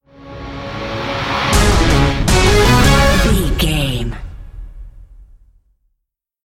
Ionian/Major
C#
horns
drums
electric guitar
synthesiser
orchestral hybrid
dubstep
aggressive
energetic
intense
strings
synth effects
driving drum beat
epic